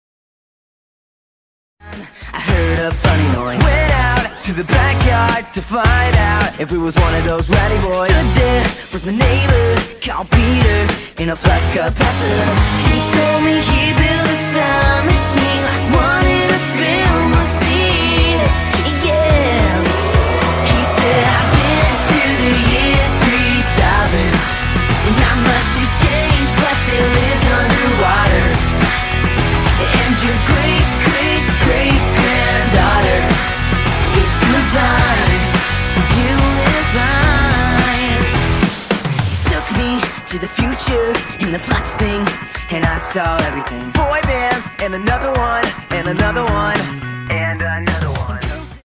ofc tiktok ruined the quality